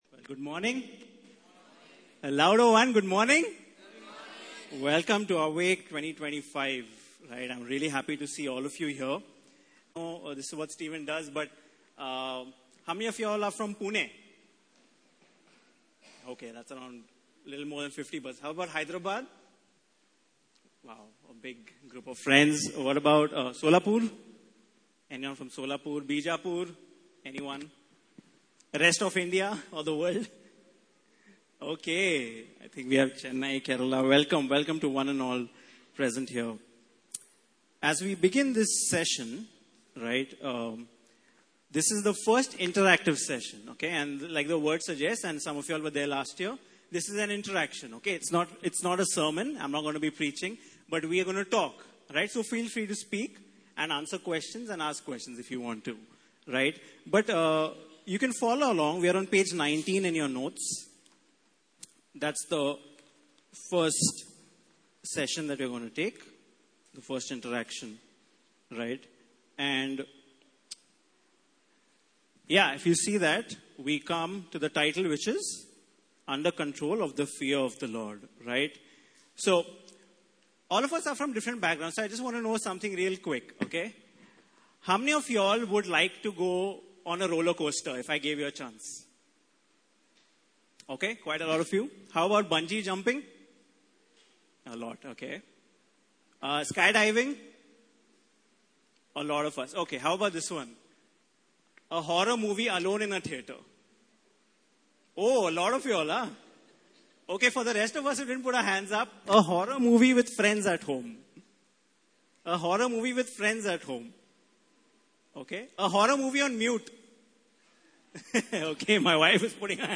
Service Type: Interactive Session